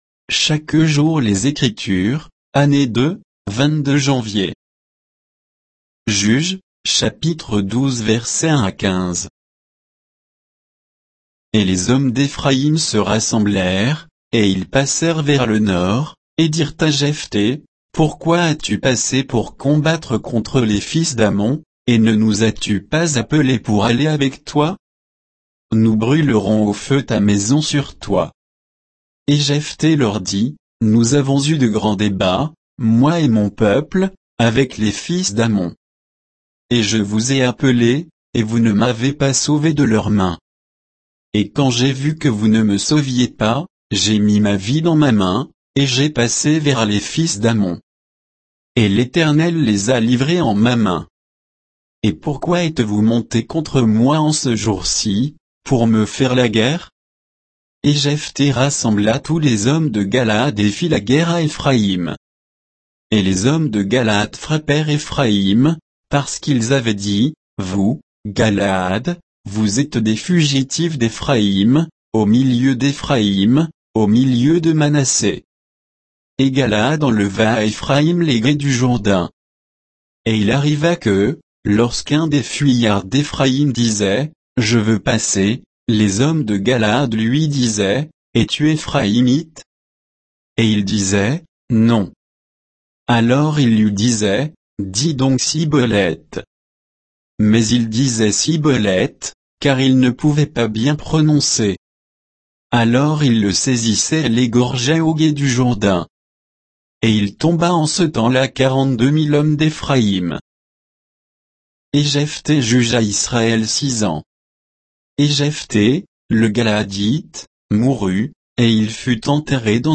Méditation quoditienne de Chaque jour les Écritures sur Juges 12